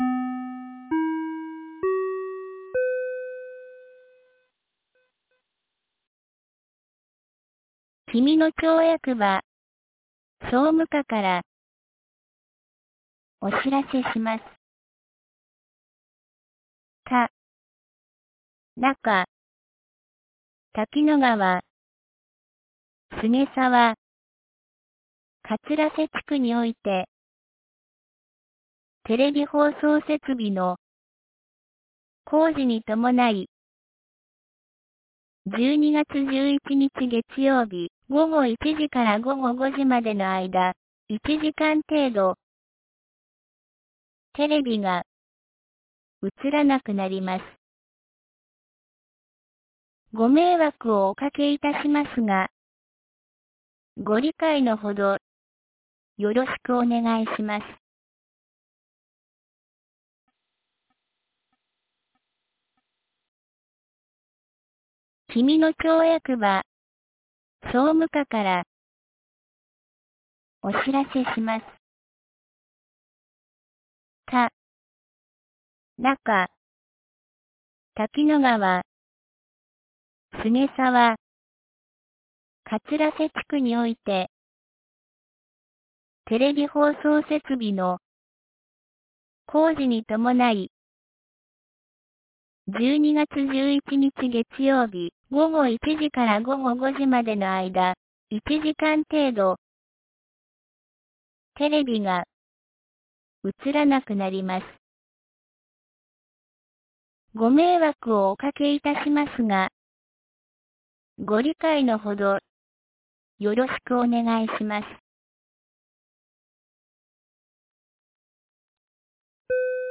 2023年12月10日 12時37分に、紀美野町より国吉地区、上神野地区へ放送がありました。